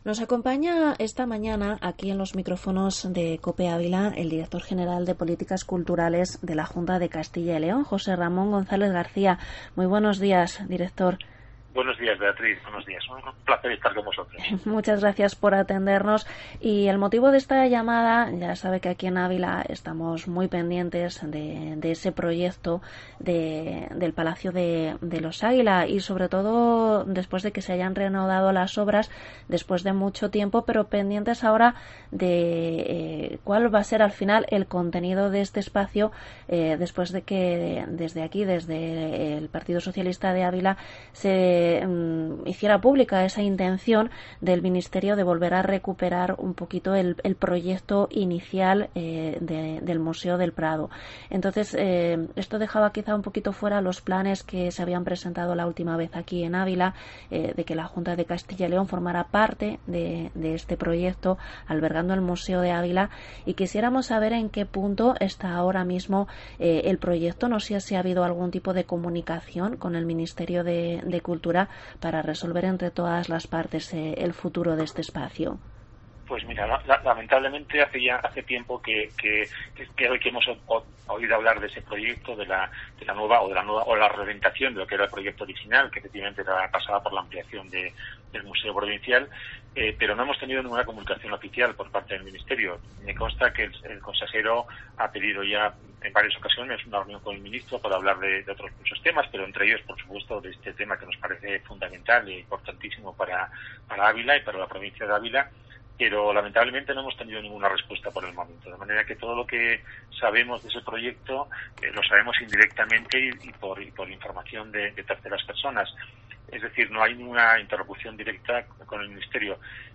Entrevista en Cope al director general de Políticas Culturales, José Ramón González García